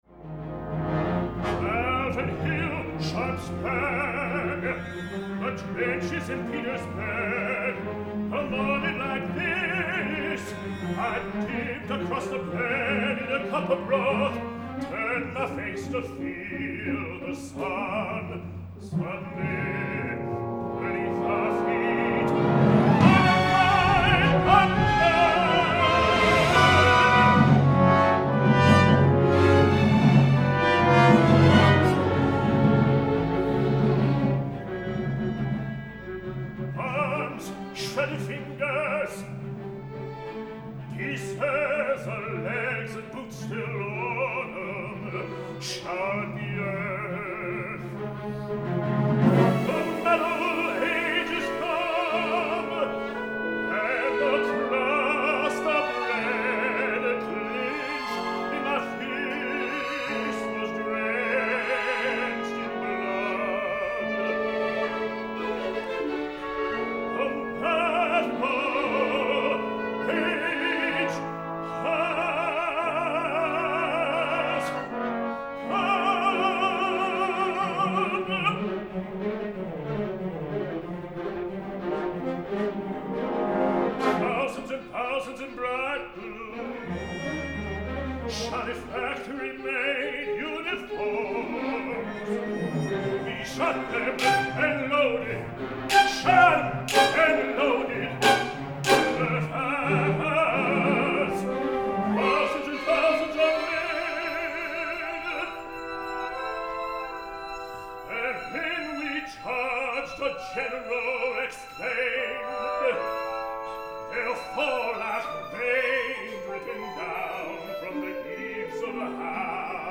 ARIAS:
Instrumentation: Baritone